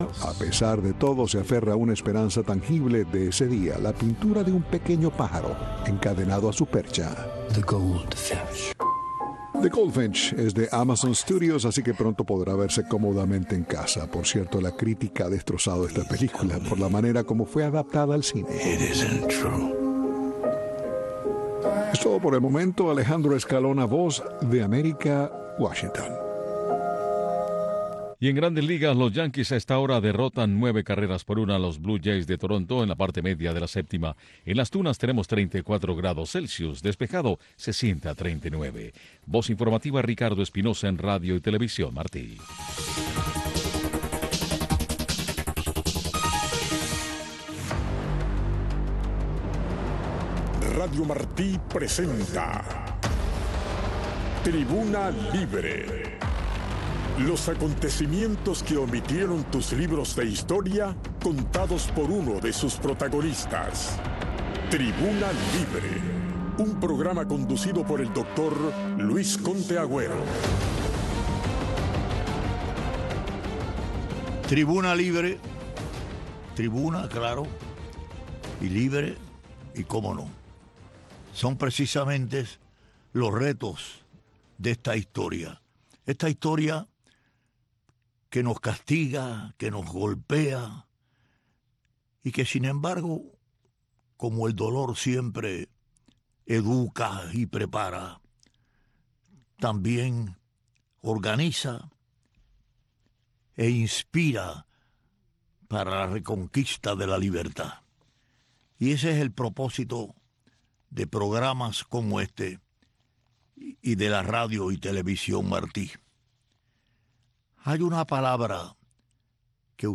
Los acontecimientos que omitieron tus libros de historia, contados por uno de sus protagonistas. Un programa conducido por el Doctor: Luis Conté Agüero.